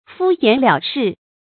注音：ㄈㄨ ㄧㄢˇ ㄌㄧㄠˇ ㄕㄧˋ
敷衍了事的讀法